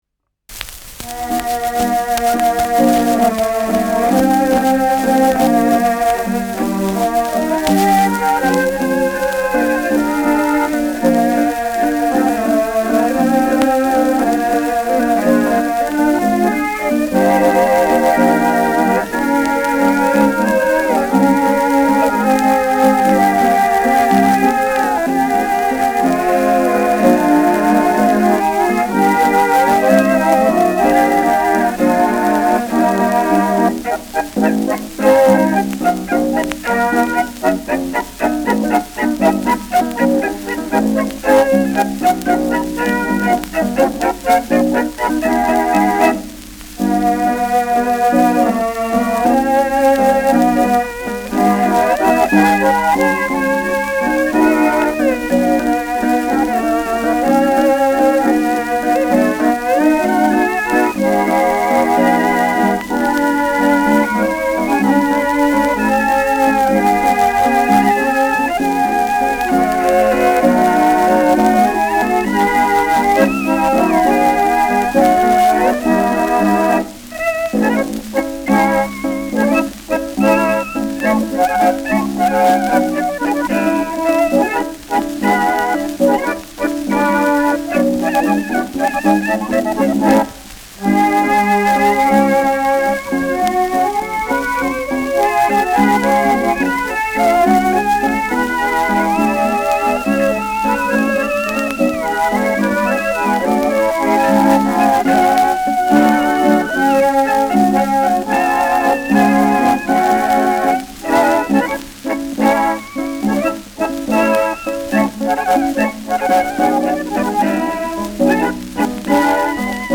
Schellackplatte
präsentes Knistern : leichtes Rauschen : abgespielt : leiert
Schrammel-Trio Schmid, München (Interpretation)